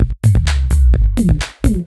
Index of /VEE/VEE2 Loops 128BPM
VEE2 Electro Loop 331.wav